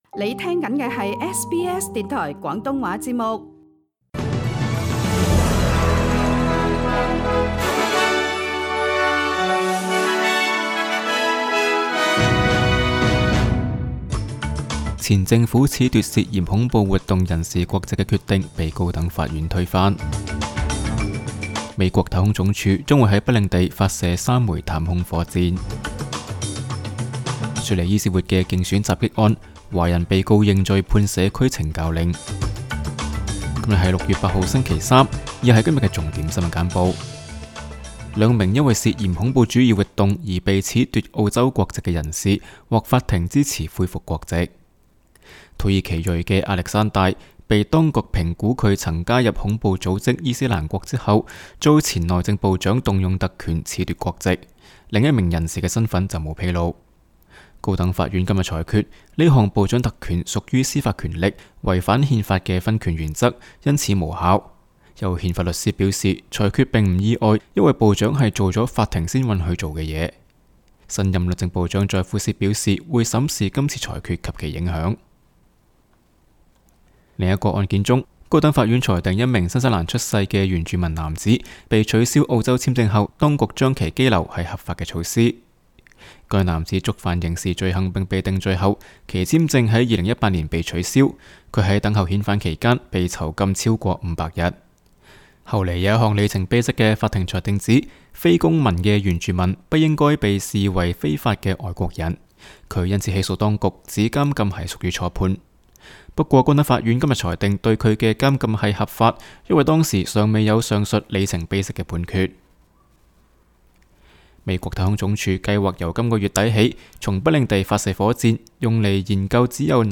SBS 新闻简报（6月8日）
SBS 廣東話節目新聞簡報 Source: SBS Cantonese